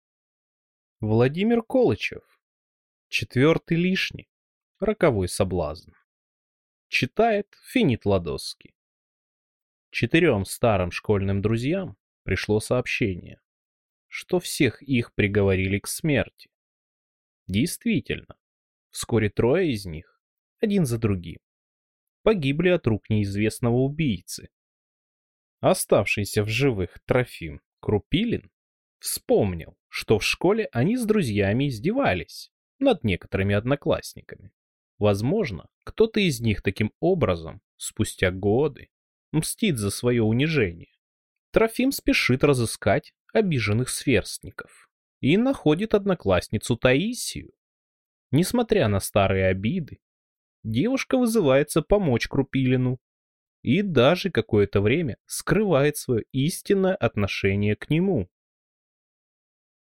Аудиокнига Четвертый лишний | Библиотека аудиокниг